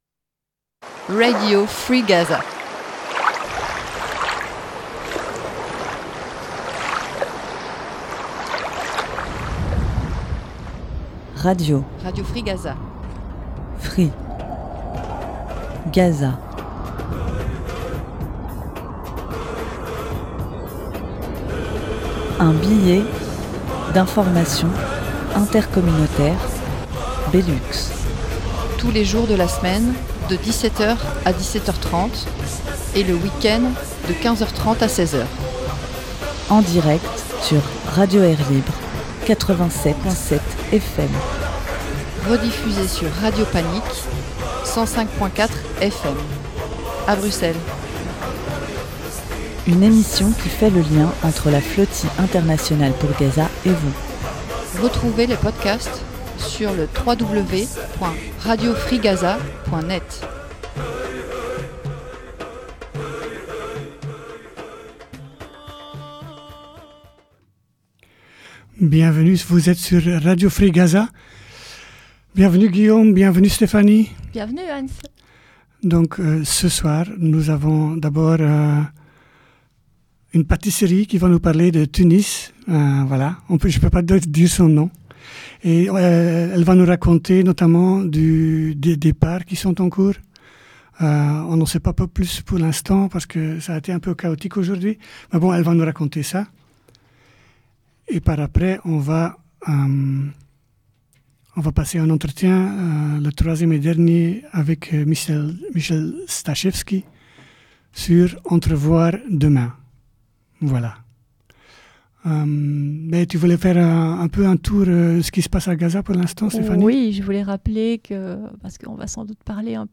D’abord nous avons pu discuter avec plusieures participantes de la délégation belge et luxembourgeoise en direct de Tunis.